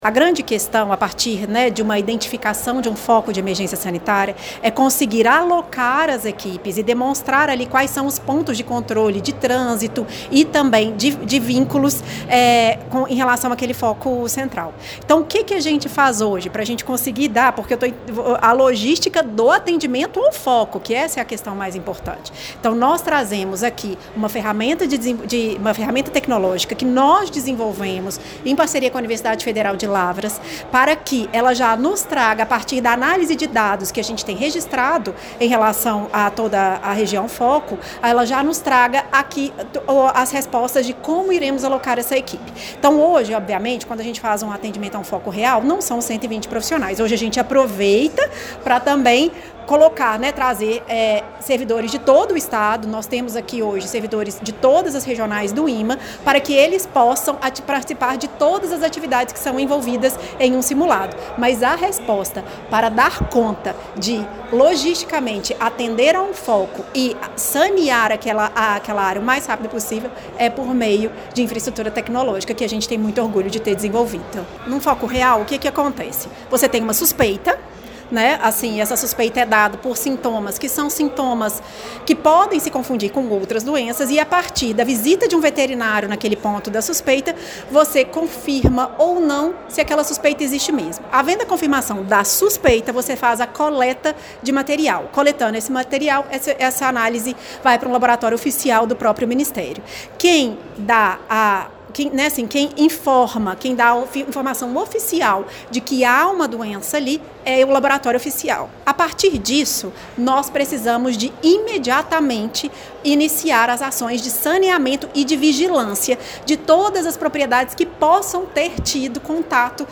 A diretora-geral do IMA, Luíza de Castro, destacou que a inovação tecnológica é peça-chave na resposta a emergências.